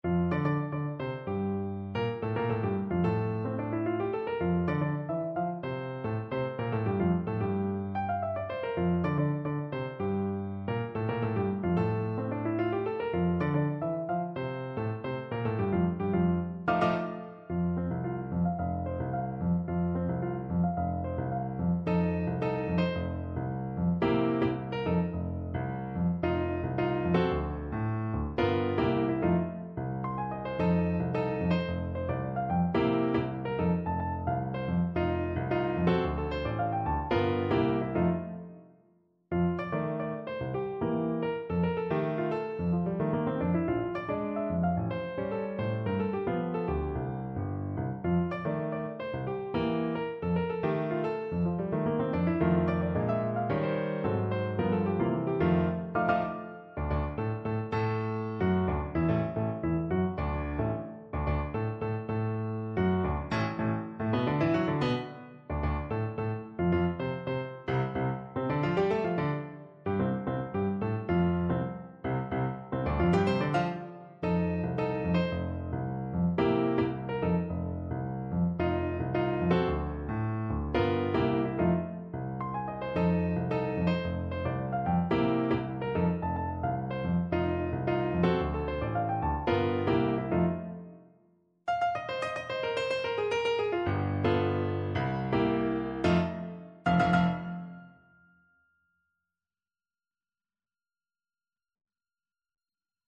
Jazzová témata / Jazz Themes